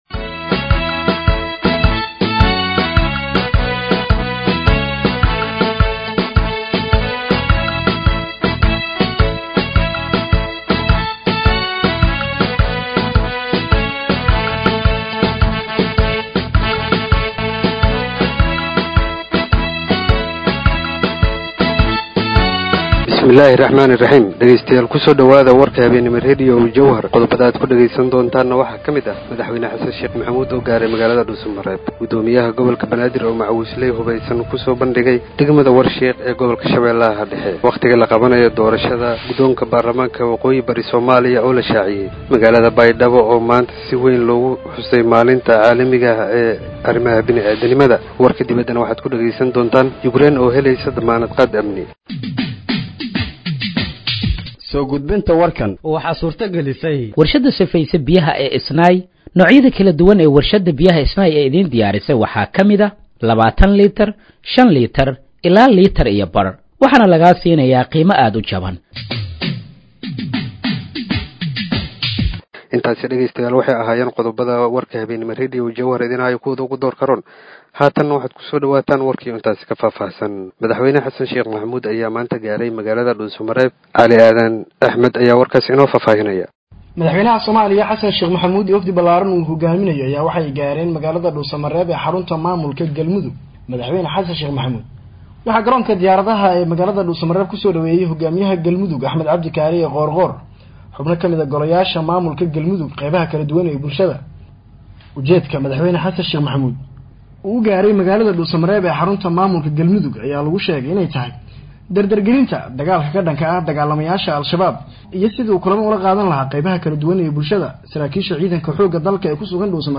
Dhageeyso Warka Habeenimo ee Radiojowhar 19/08/2025
Halkaan Hoose ka Dhageeyso Warka Habeenimo ee Radiojowhar